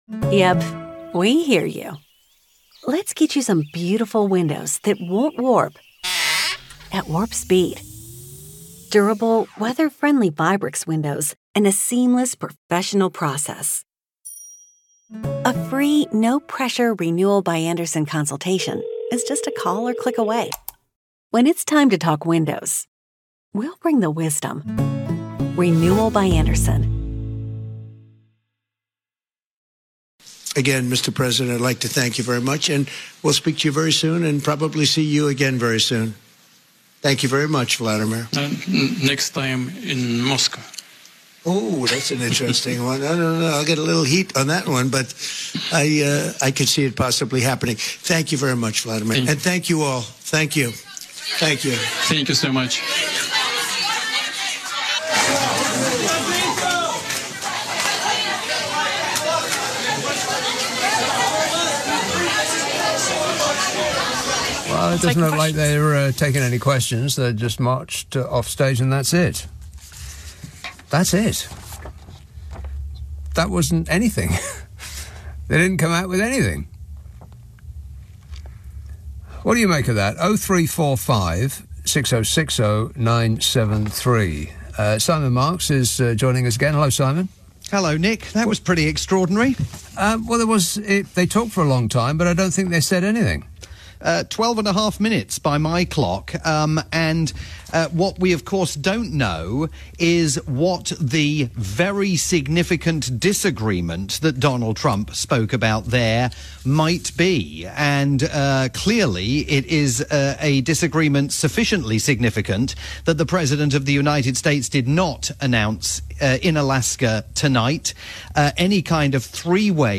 August 15, 2025 - AS IT BROKE: Live analysis of Trump/Putin press statement